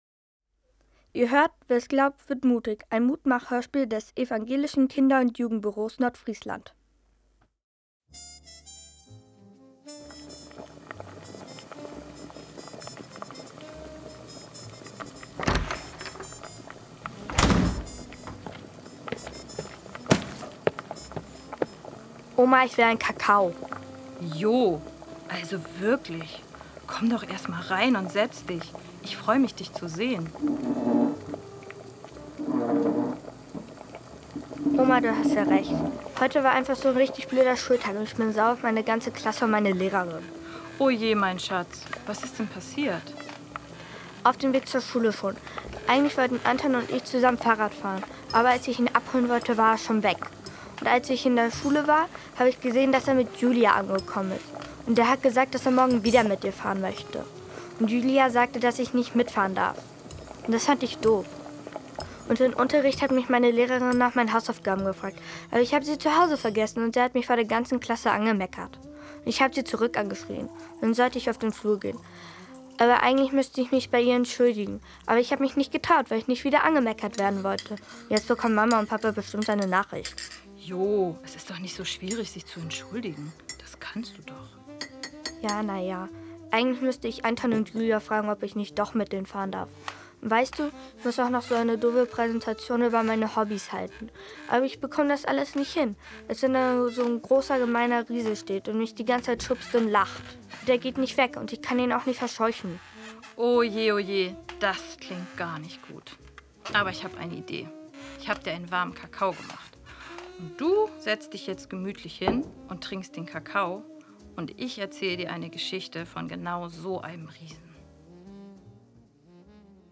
Unser eigenes Kindergruppen-Hörspiel: